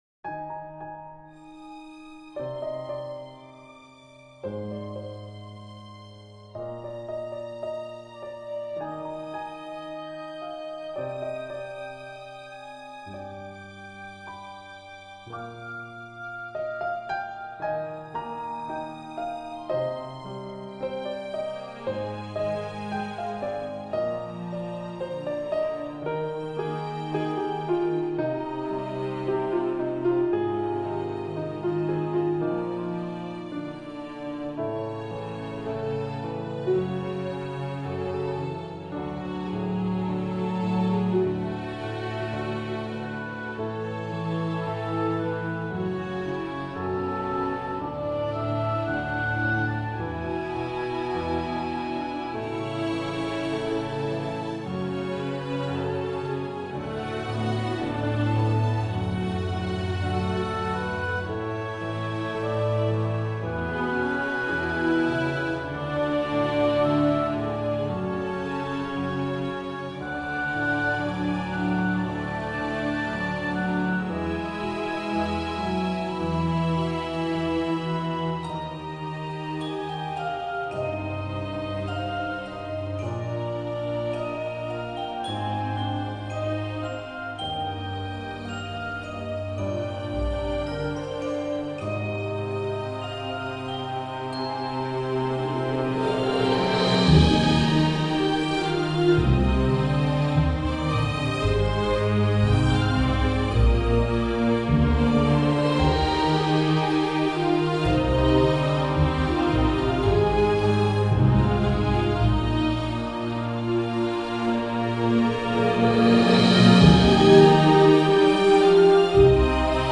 genre:acoustic